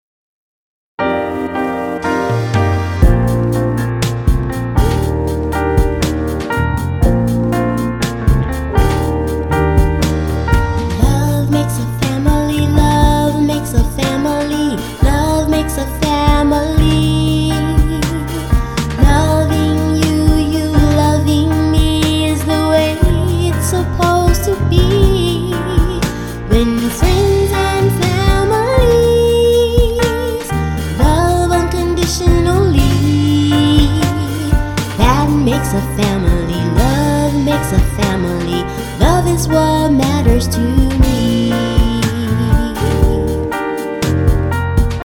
doo-wop and 80s R&B inspired
All tracks except Radio Edits include scripted dialogue.